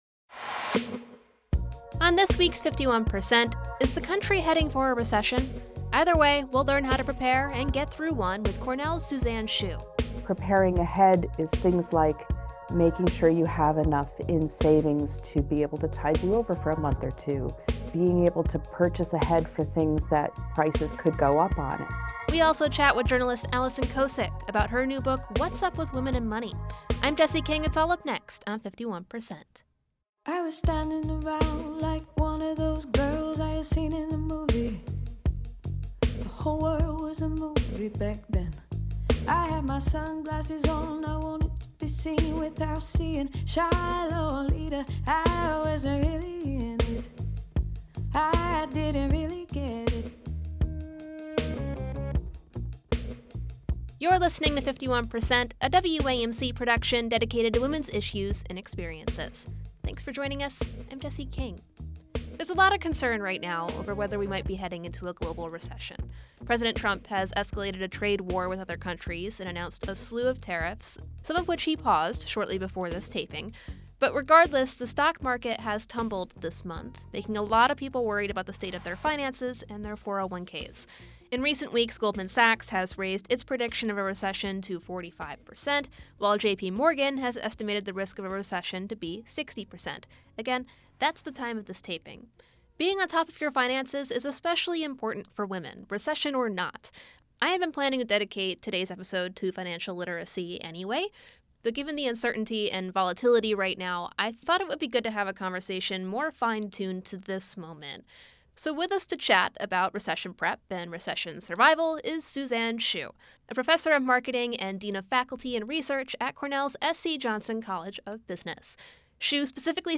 How To Do All the Financial Stuff You’ve Been Avoiding 51% is a national production of WAMC Northeast Public Radio in Albany, New York.